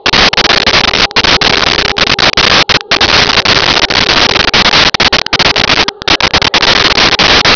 Sfx Amb Jungle H Loop
sfx_amb_jungle_h_loop.wav